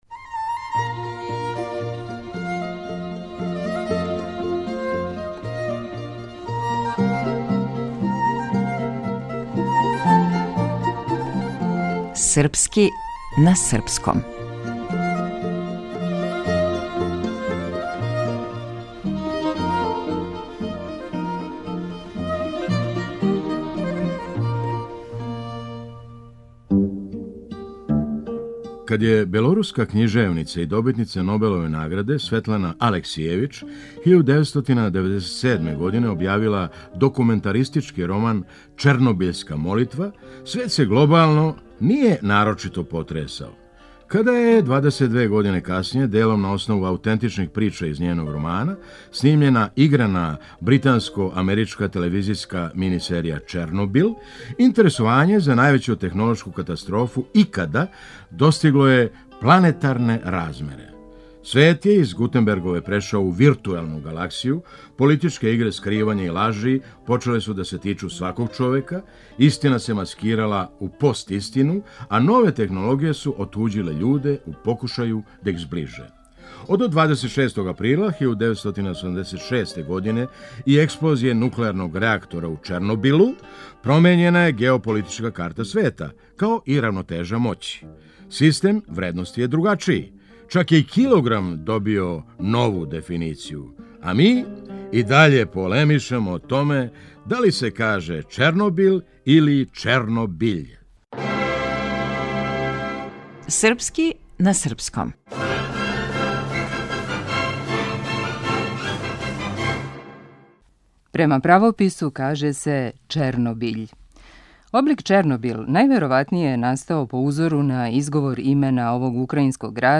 Глумац: Феђа Стојановић